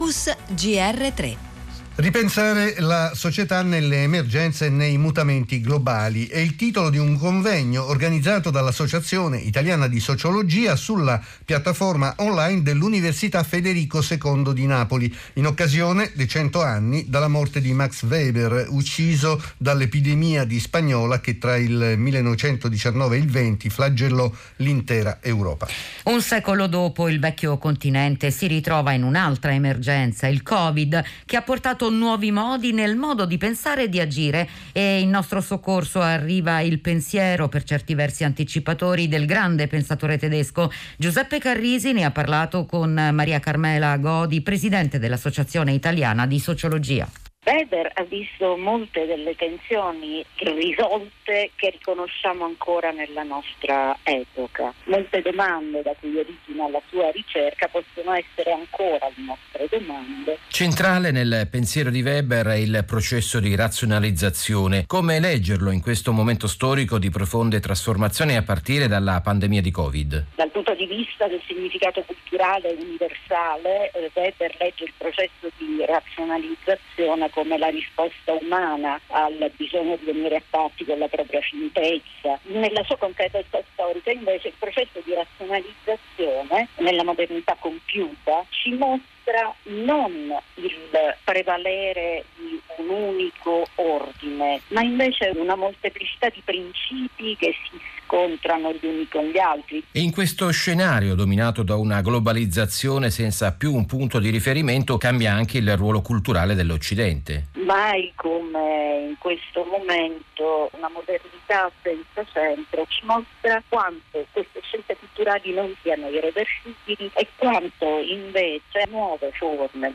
Rai Giornale Radio
Intervista